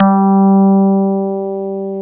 Index of /90_sSampleCDs/Club-50 - Foundations Roland/PNO_xFM Rhodes/PNO_xFM Rds C x2